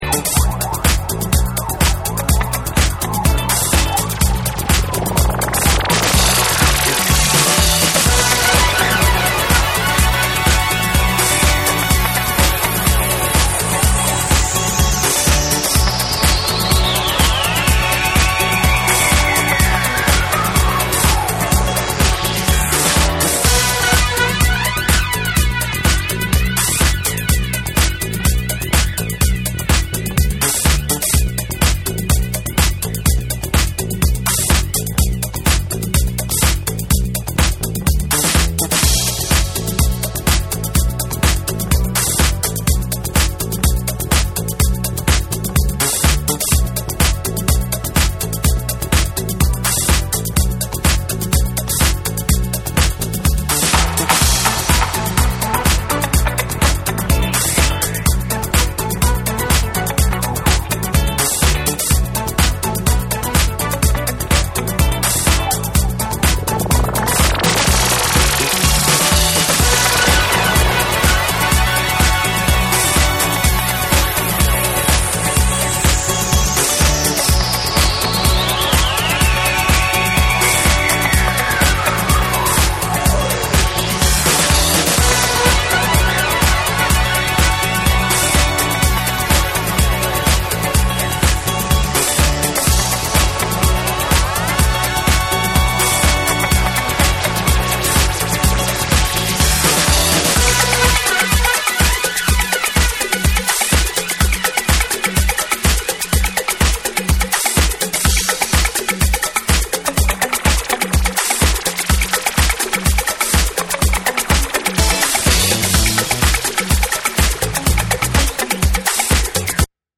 TECHNO & HOUSE / DISCO DUB